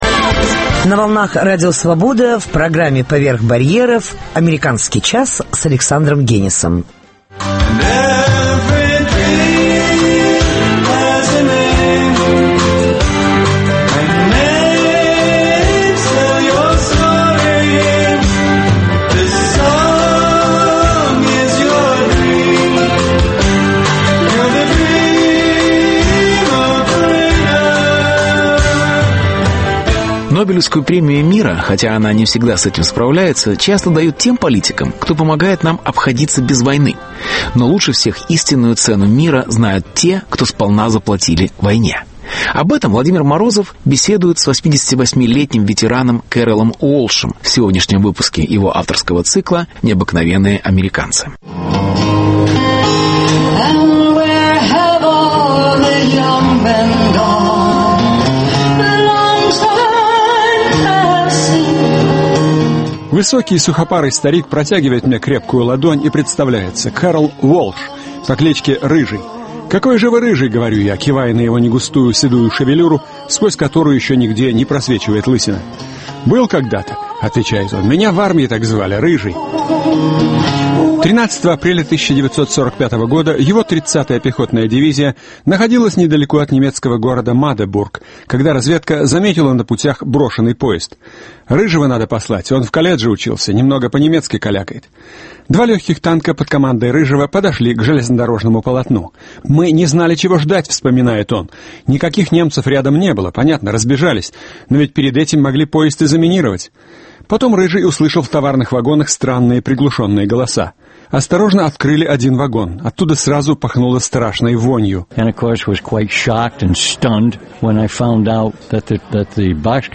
Интервью.